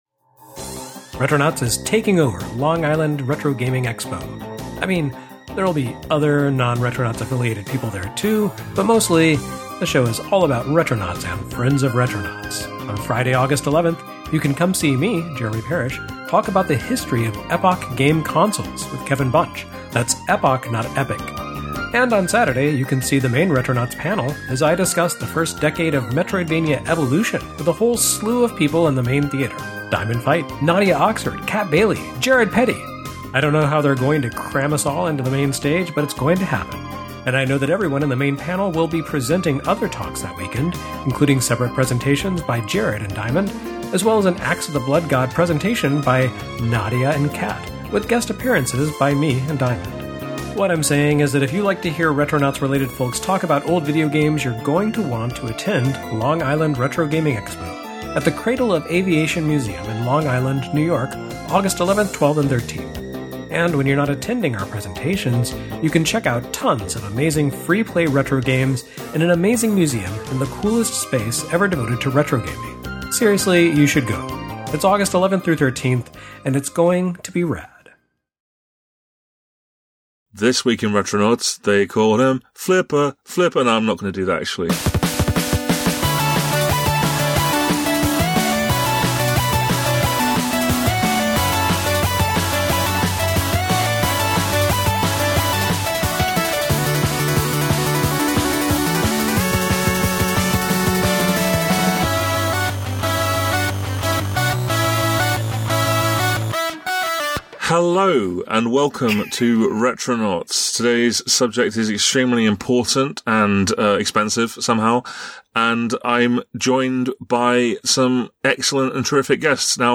Clang! Blong!! Flenk!!! and other pinball noises.